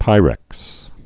(pīrĕks)